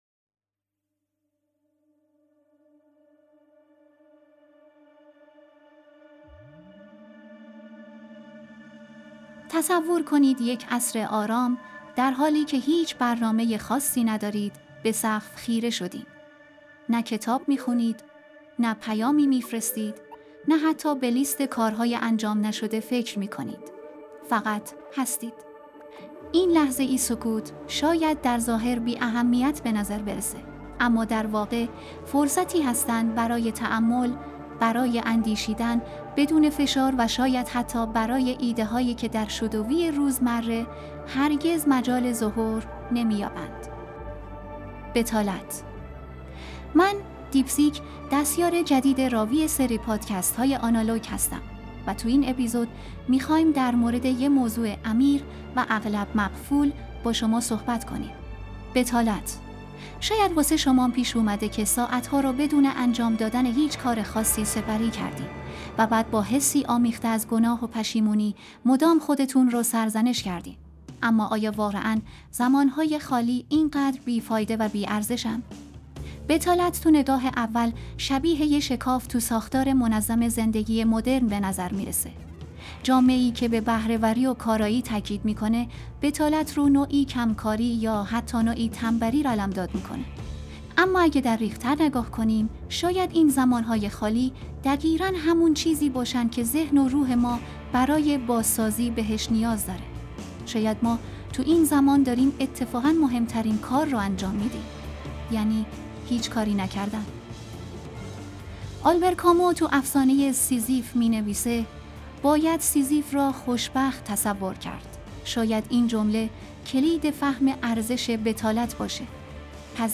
اپیزودهای ما در این شروع جدید، برخلاف فصل قبلی که با دیالوگی بین انسان و هوش مصنوعی (جمینی) شروع میشد، این بار با مونولوگی از یک هوش مصنوعی متفاوت (دیپ سیک ) شروع می‌شود.